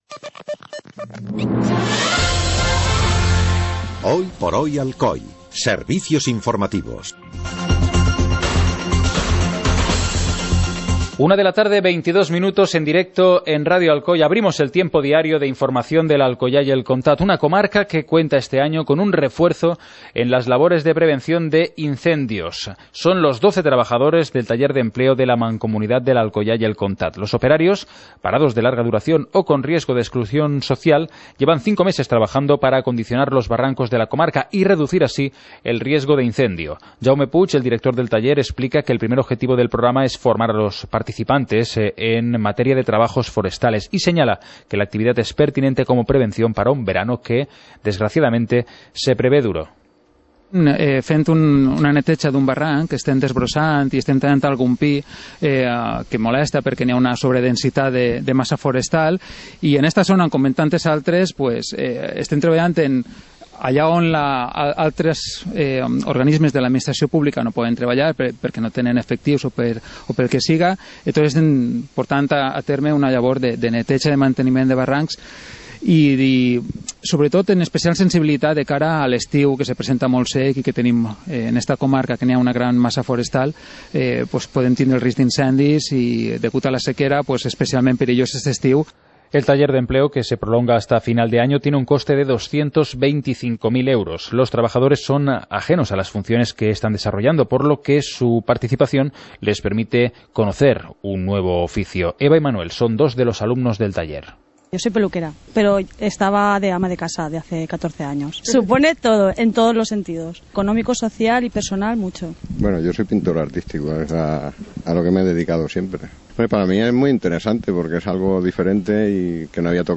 Informativo comarcal - jueves, 05 de junio de 2014